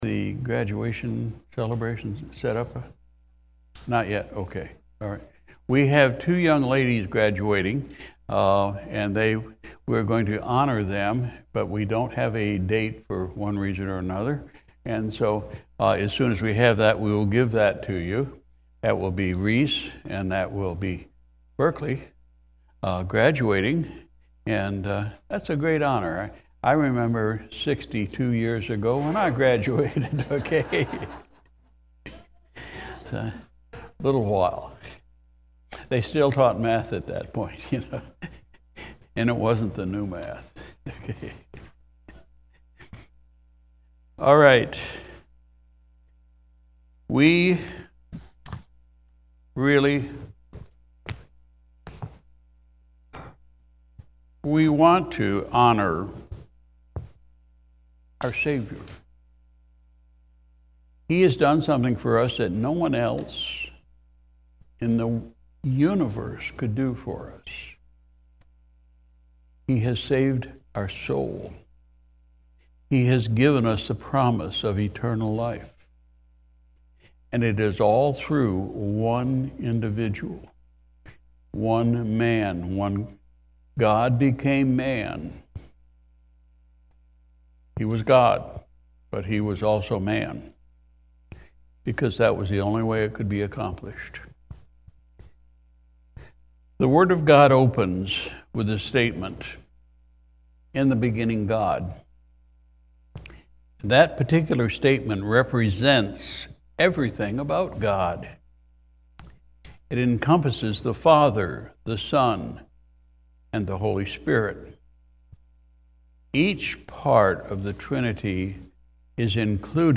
May 7, 2023 Sunday Morning Service Pastor’s Message: “The Superiority of Jesus”